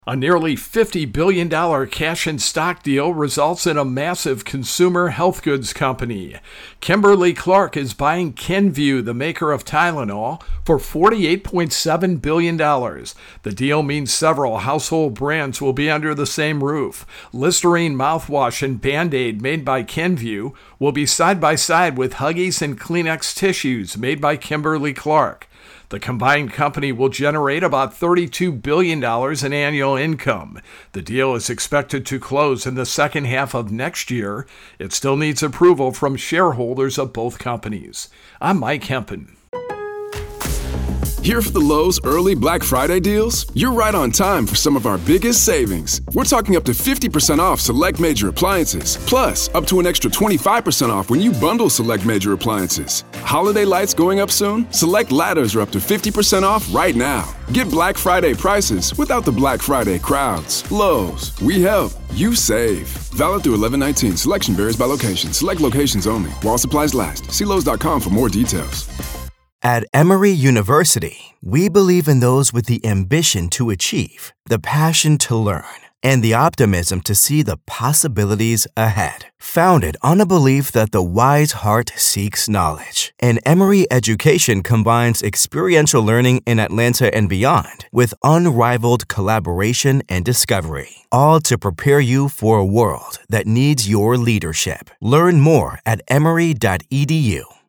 A major corporate takeover has been announced. AP correspondent